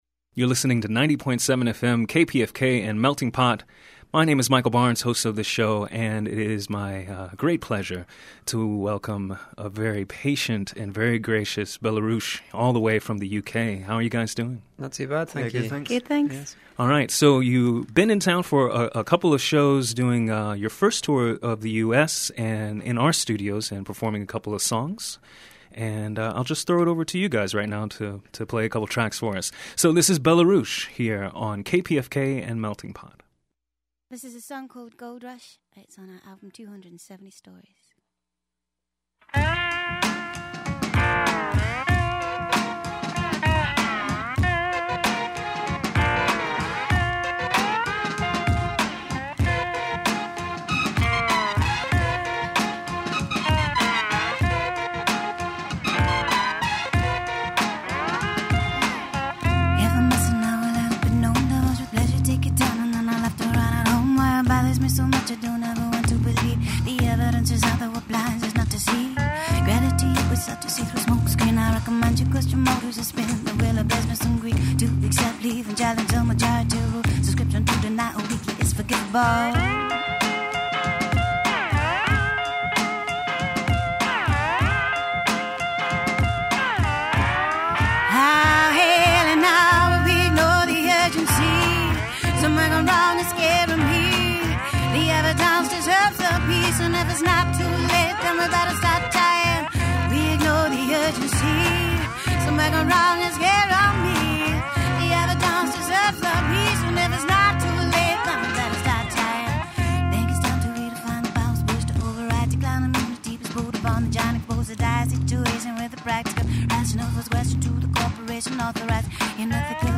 the band performed live in our studios